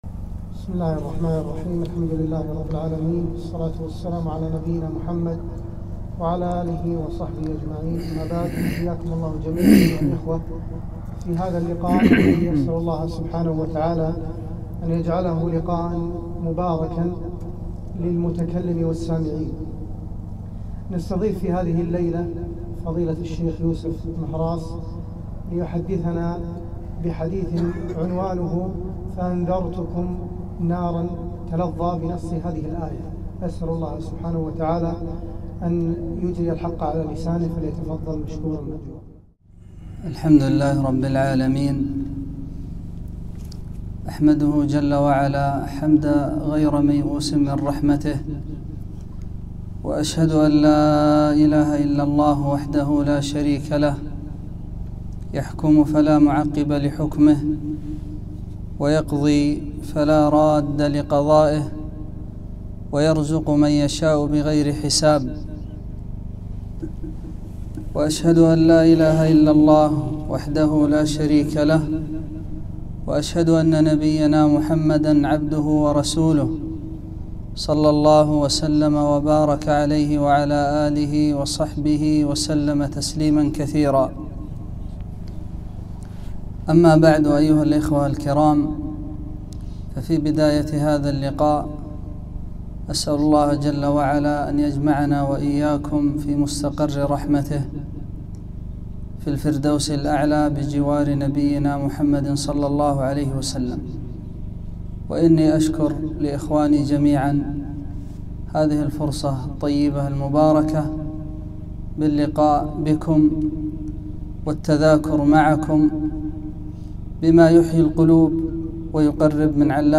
محاضرة - فأنذرتكم ناراً تلظى